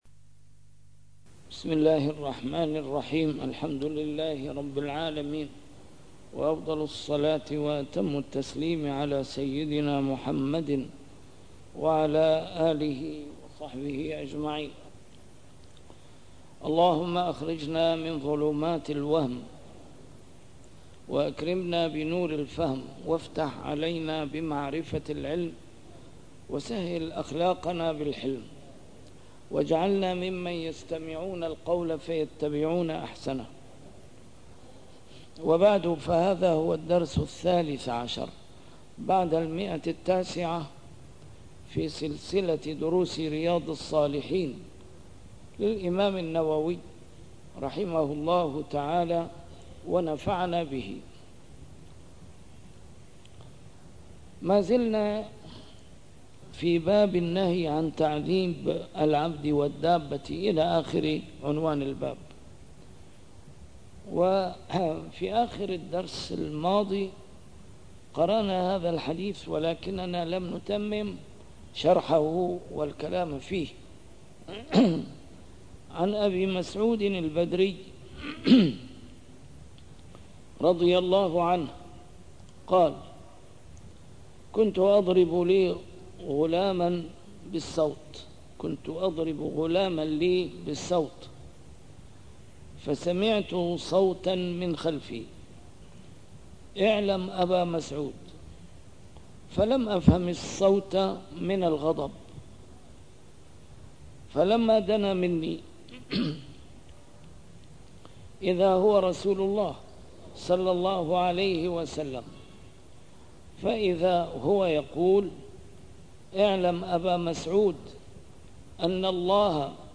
A MARTYR SCHOLAR: IMAM MUHAMMAD SAEED RAMADAN AL-BOUTI - الدروس العلمية - شرح كتاب رياض الصالحين - 913- شرح رياض الصالحين: النهي عن تعذيب العبد - تحريم التعذيب بالنار